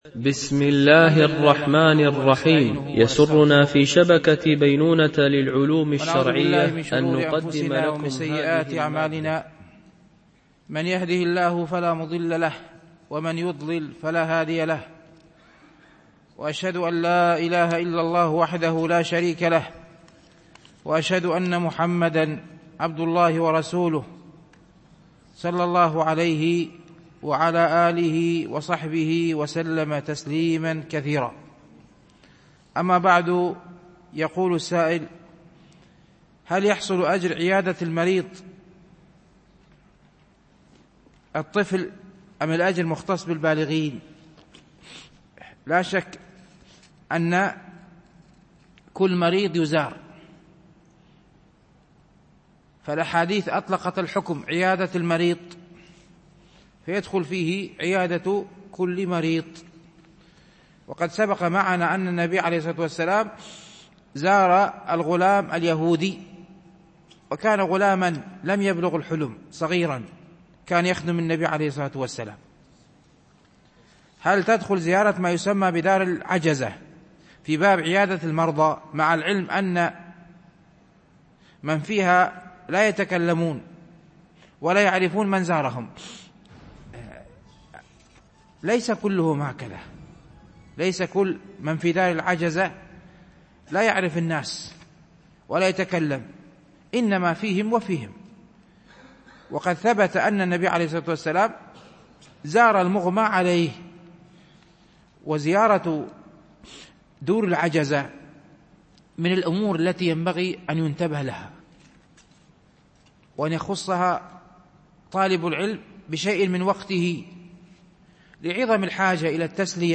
شرح رياض الصالحين – الدرس 238 ( الحديث 906 – 912 )